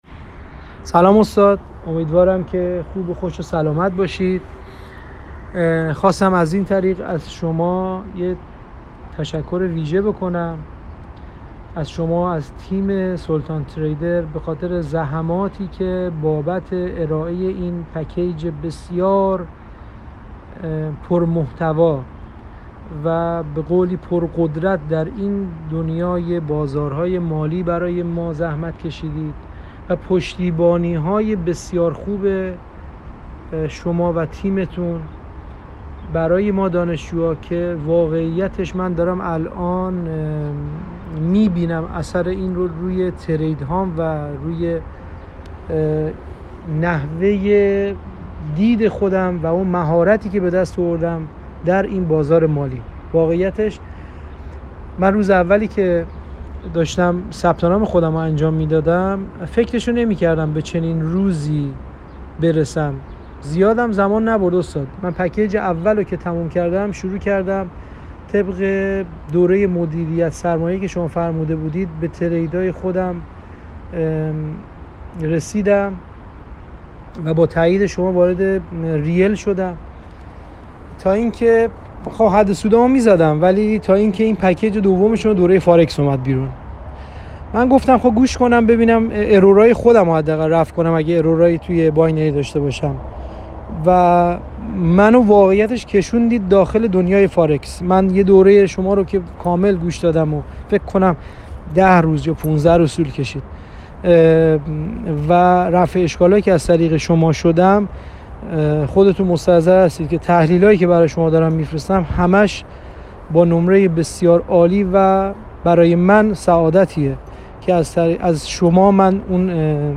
صدای دانشجویان دوره :